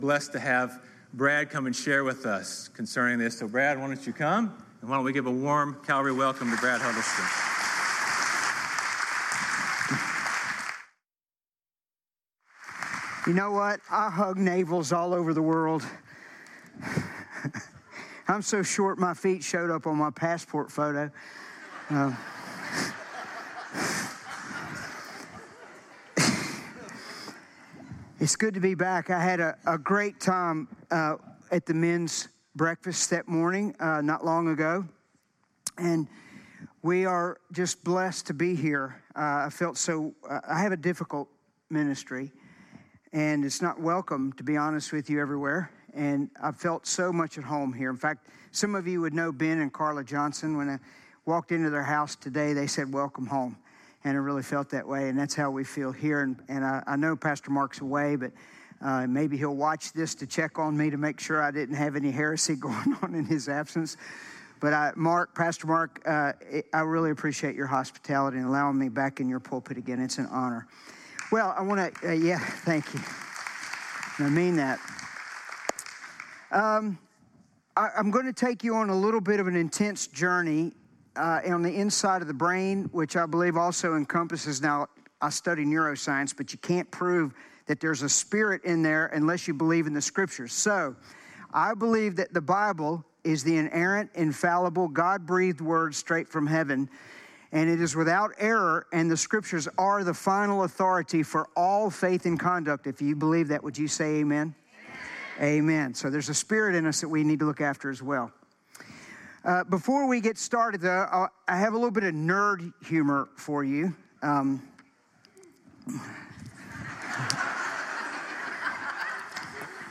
sermons How Technology Affects Intimacy With God